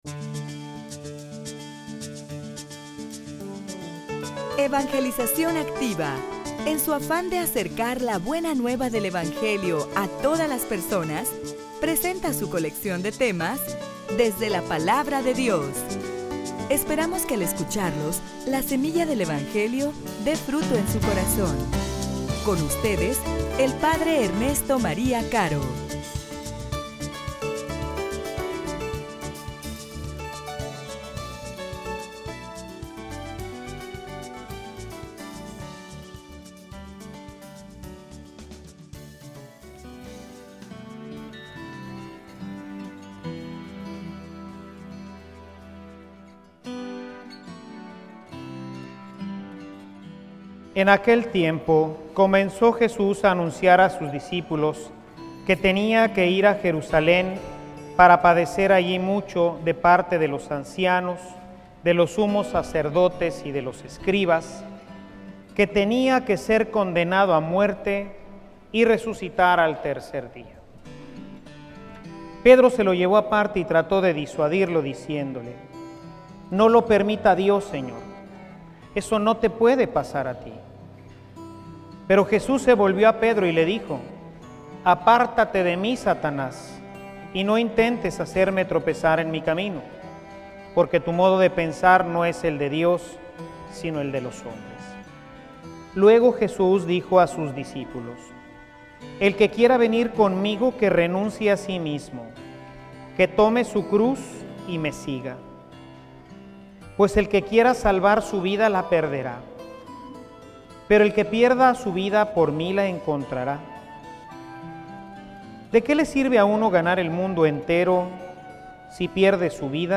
homilia_Debo_ir_a_Jerusalen.mp3